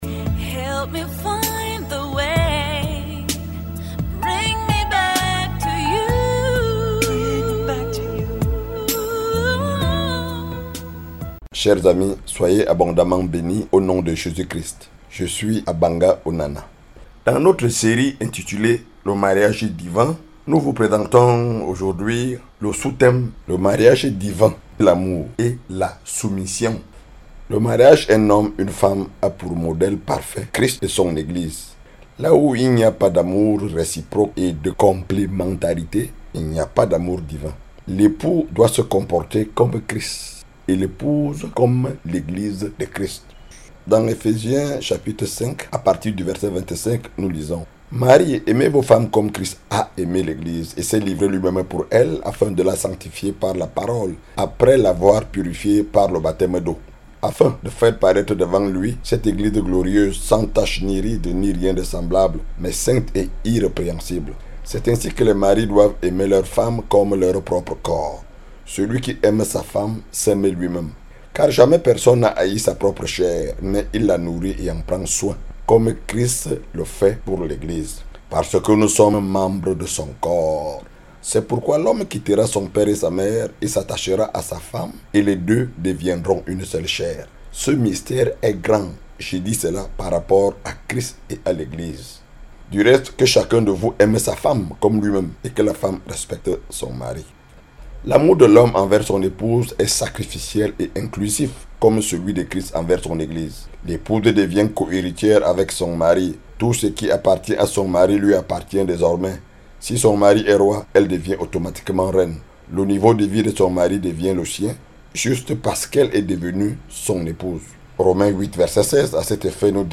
Evangelist